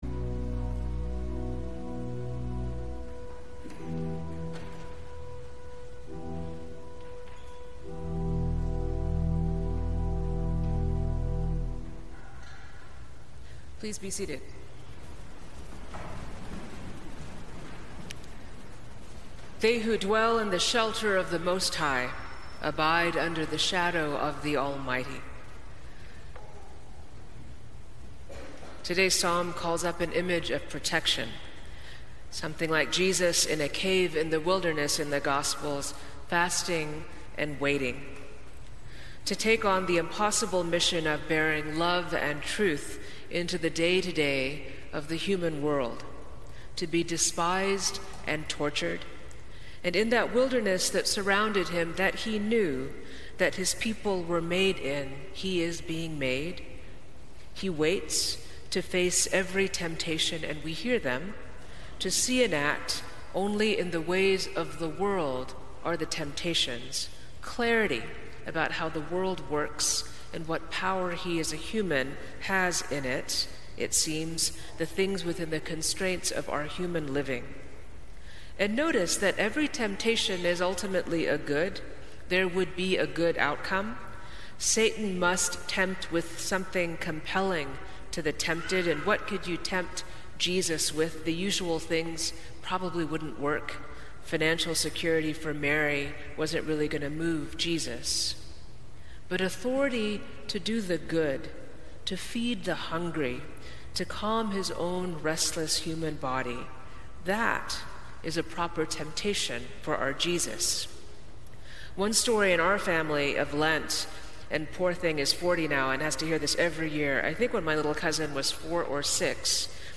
Sermons from St. Luke's Episcopal Church in Atlanta